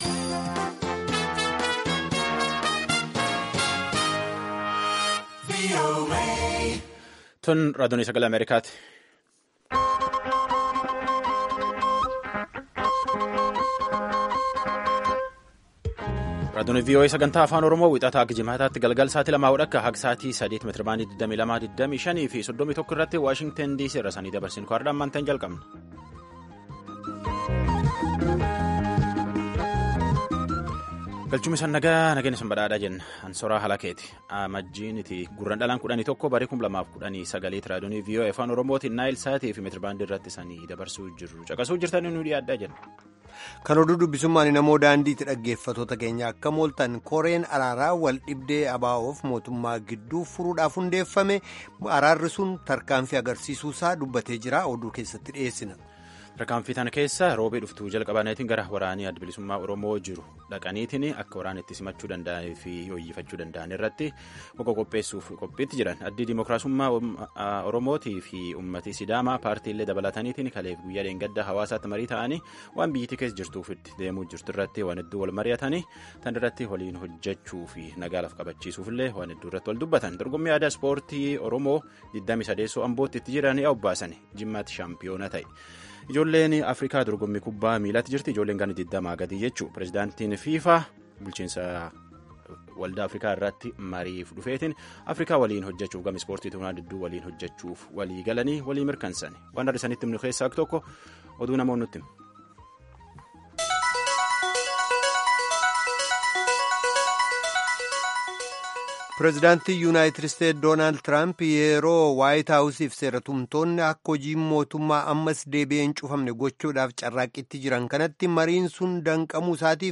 Half-hour broadcasts in Afaan Oromoo of news, interviews with newsmakers, features about culture, health, youth, politics, agriculture, development and sports on Monday through Friday evenings at 8:30 in Ethiopia and Eritrea.